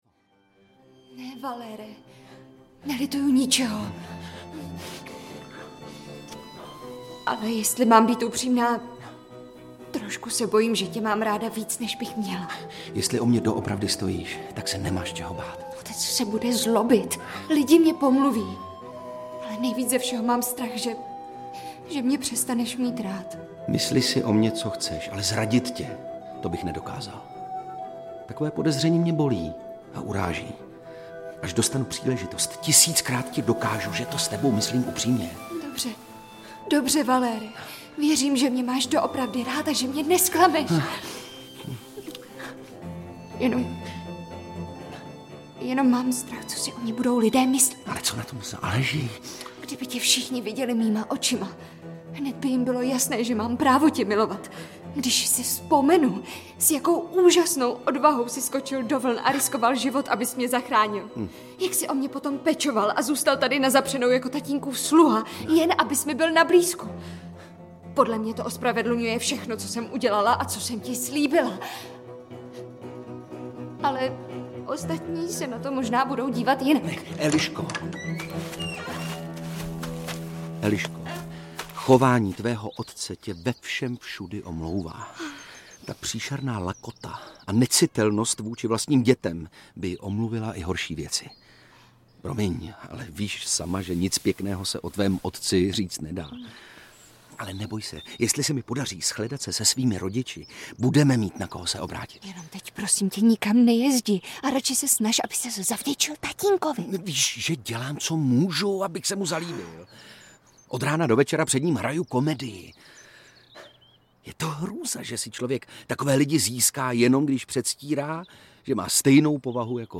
Lakomec audiokniha
Audio kniha
Ukázka z knihy